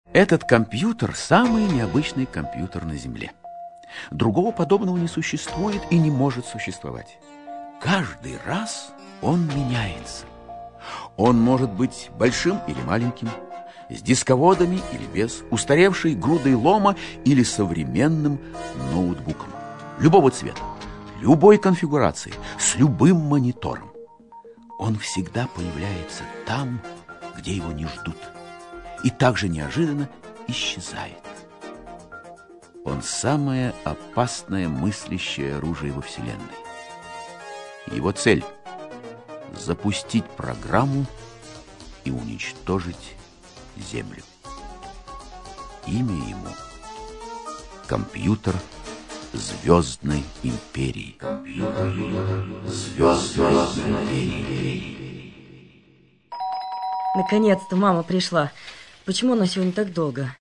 Аудиокнига Месть компьютера (спектакль) | Библиотека аудиокниг
Прослушать и бесплатно скачать фрагмент аудиокниги